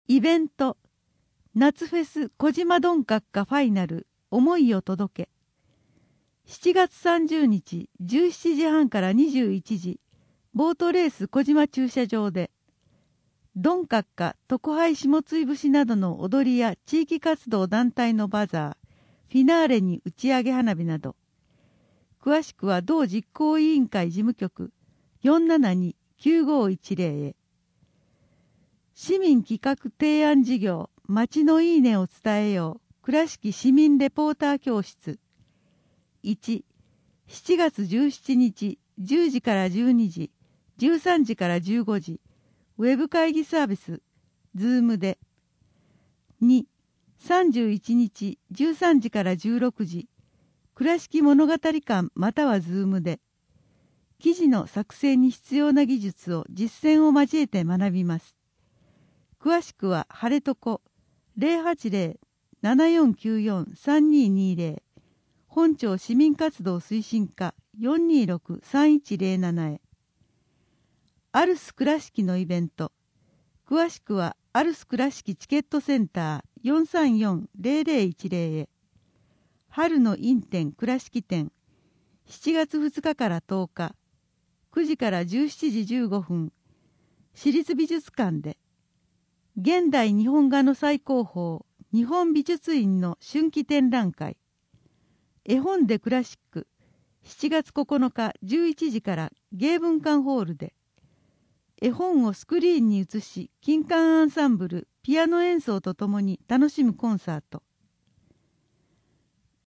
広報くらしき７月号（音訳版）/くらしき情報発信課/倉敷市